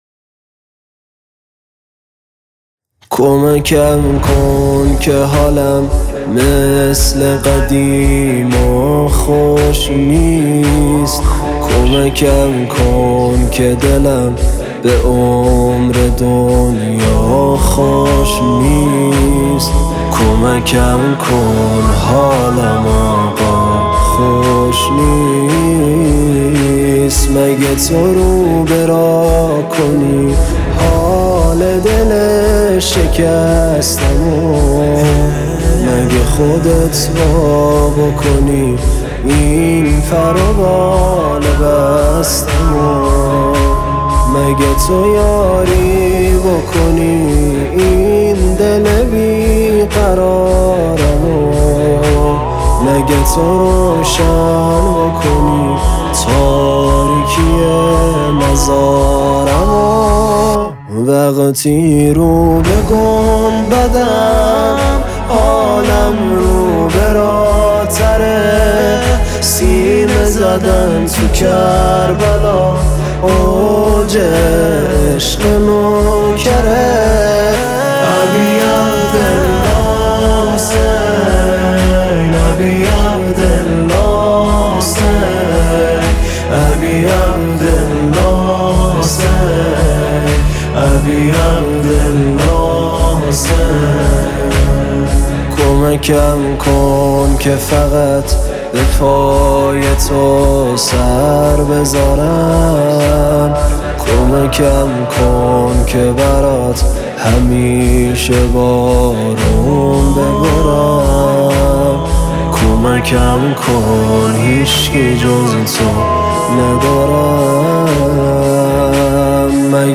دانلود مداحی